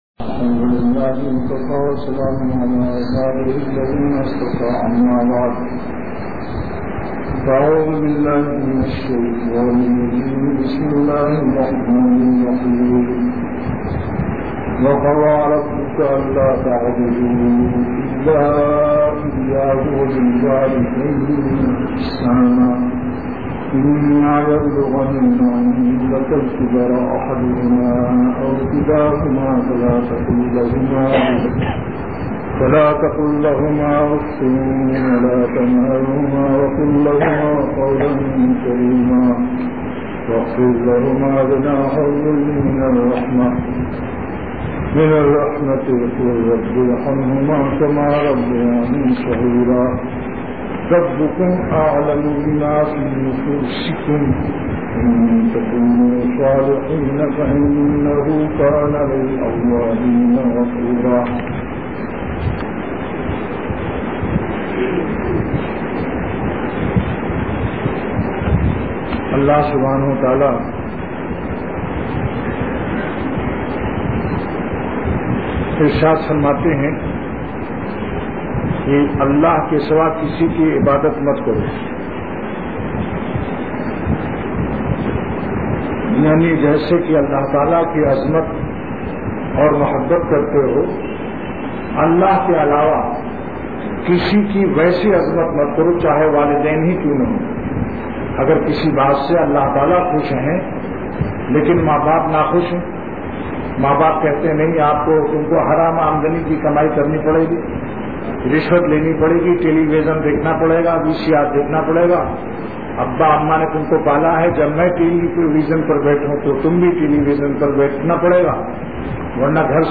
Delivered at Khanqah Imdadia Ashrafia.